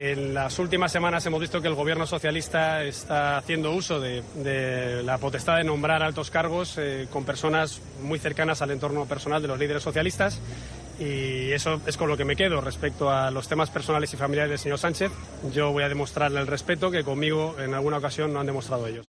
"Y que no han colaborado en el esclarecimiento de los 300 crímenes que siguen impunes y que ni siquiera están ayudando a establecer un relato de la derrota del terrorismo en la que tiene que haber vencidos y vencedores", ha señalado Casado en declaraciones a los periodistas en Santa Pola (Alicante).